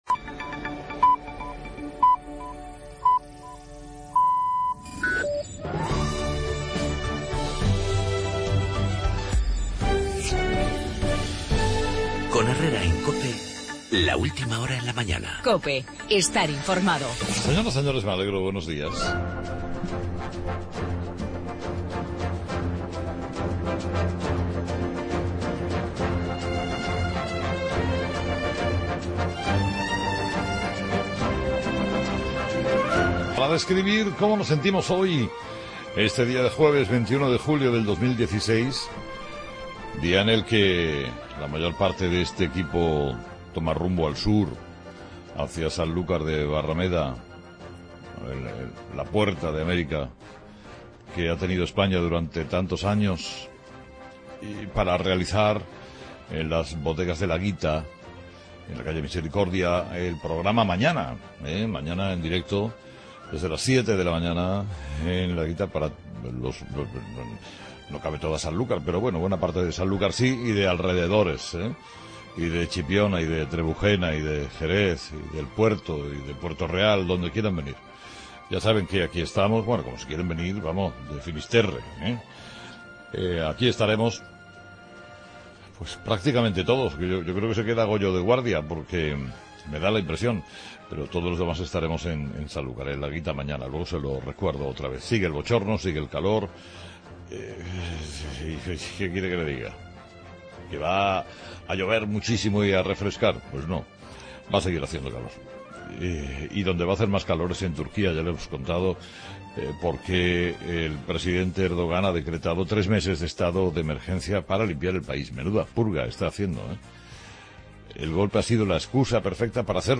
Los tres meses de 'estado de emergencia' decretados por Erdogan en Turquía; el enfado de Ciudadanos con los votos nacionalistas al PP en la constitución de la Mesa del Congreso; la posible alternativa aritmética a Rajoy si Ciudadanos no acaba apoyando al PP; en el editorial de Carlos Herrera a las 8 de la mañana.